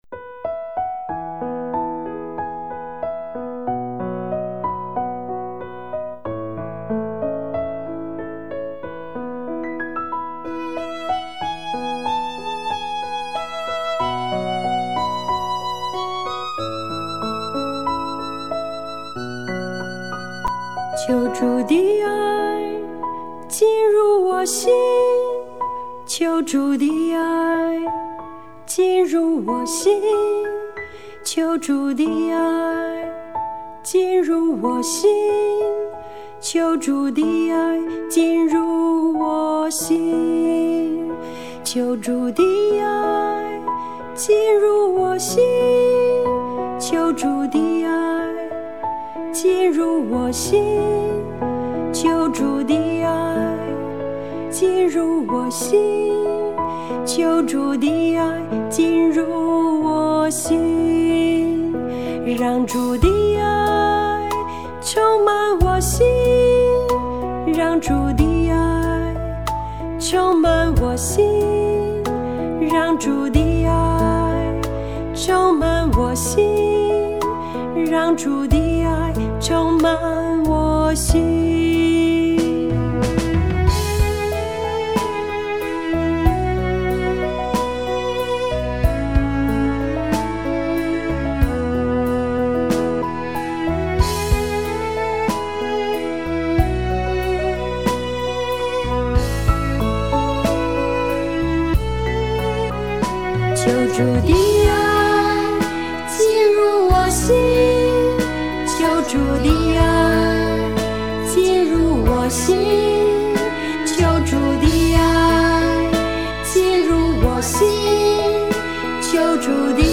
儿童赞美诗| 爱充满我心